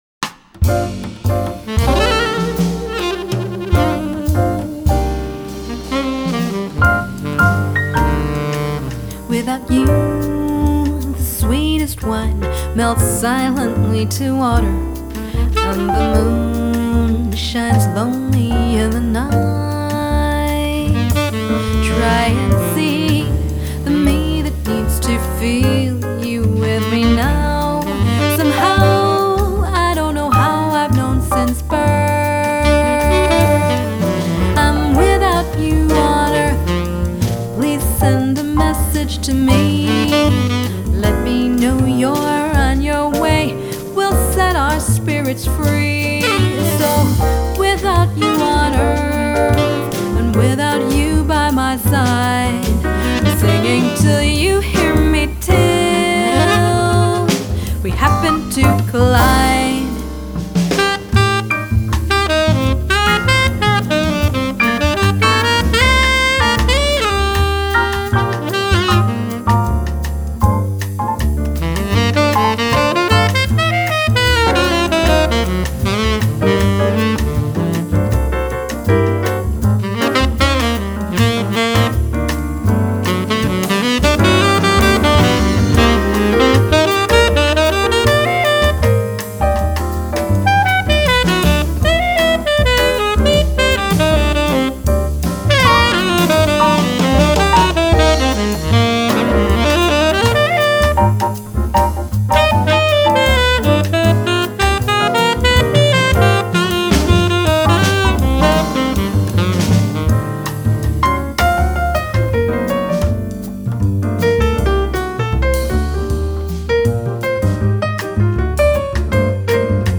tenor sax